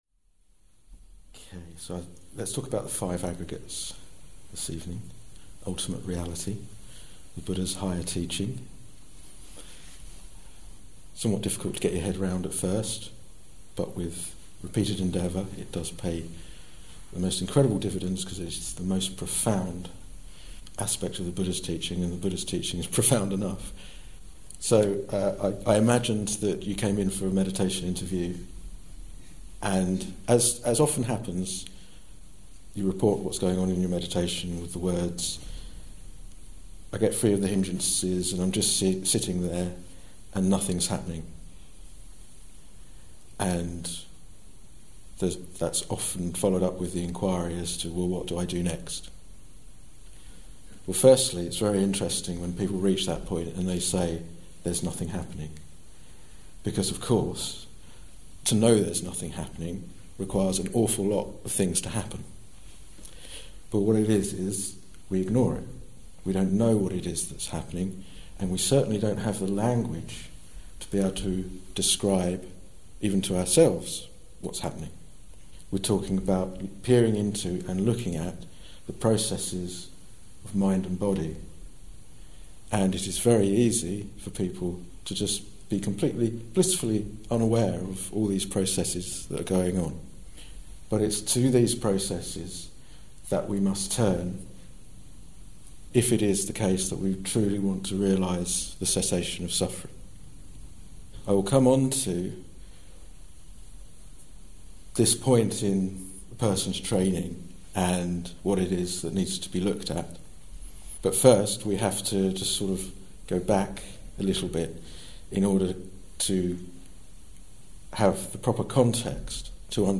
A somewhat more technical talk about the Buddha's higher teaching on the constituents of ultimate reality.